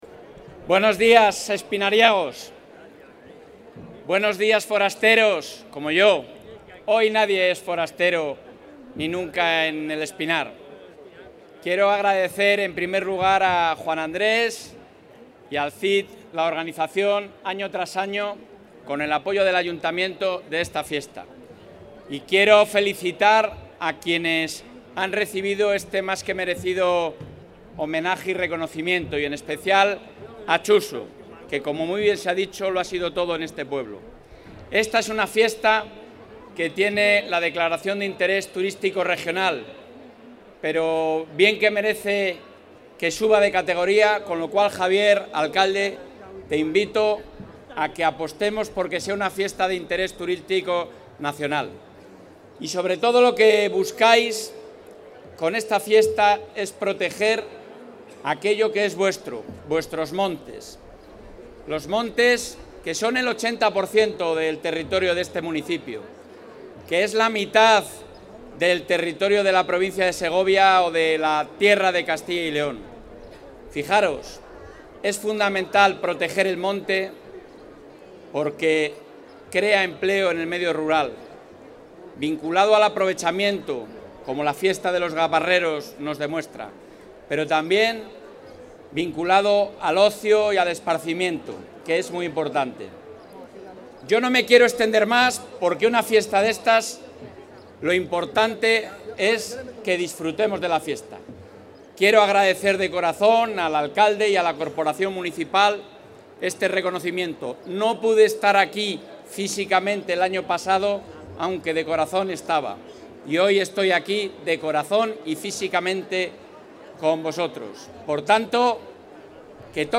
Intervención del presidente de la Junta.
El presidente de la Junta ha asistido a la XXIV de la Fiesta de los Gabarreros de El Espinar, que comenzó el pasado día 4 de marzo y finalizan hoy con diferentes actividades programadas